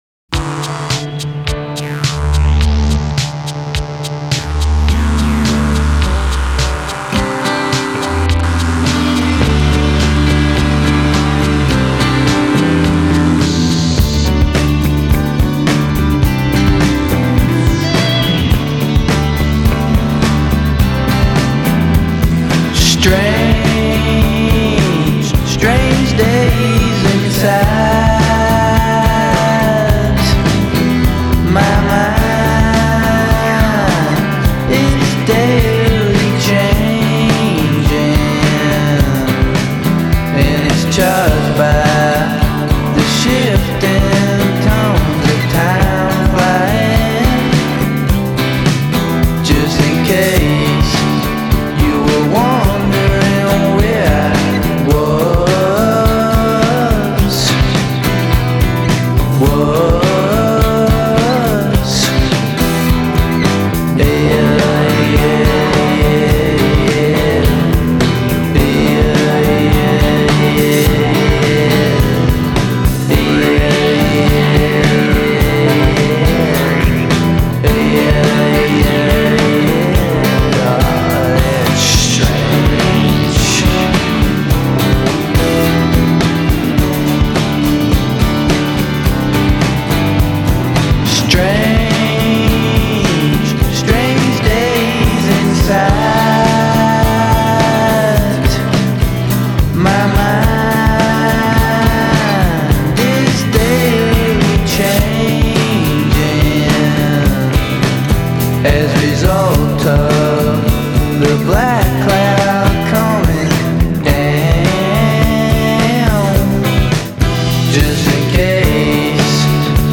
and my god! the acoustic guitar detail at the end!